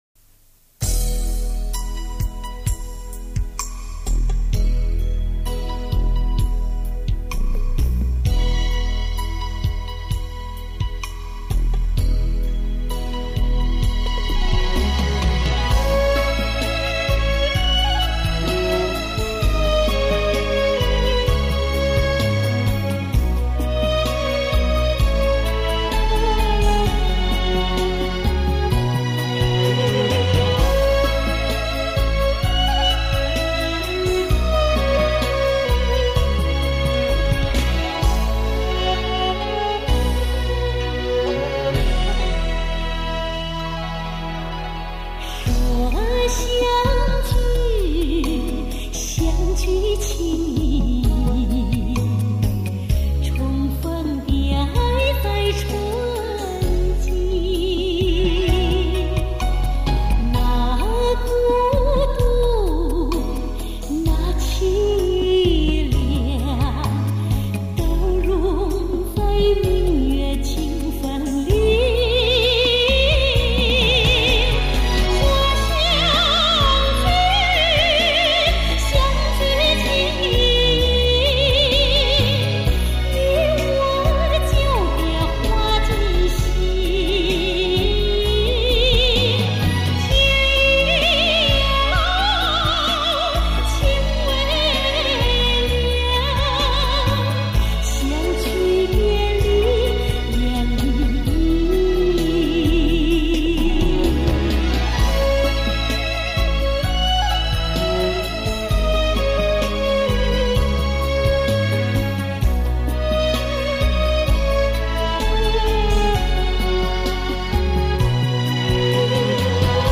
类型: HIFI试音